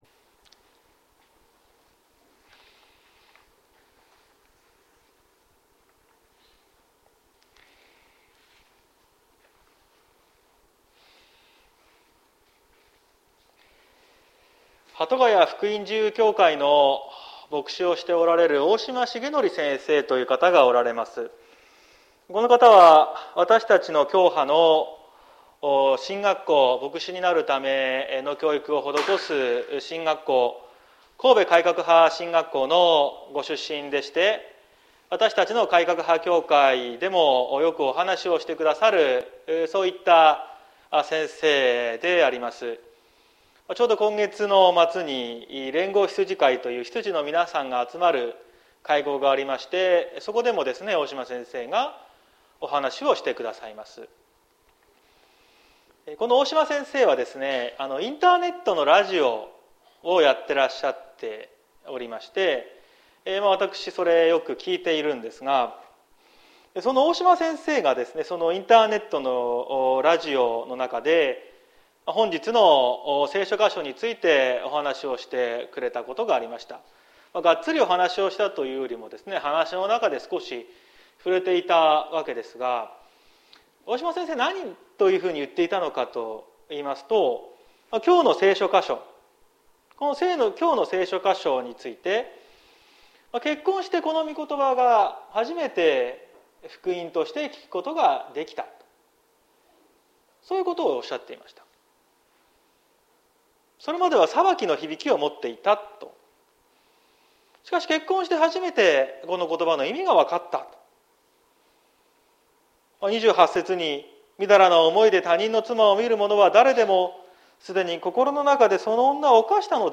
2022年10月09日朝の礼拝「真実な結婚」綱島教会
説教アーカイブ。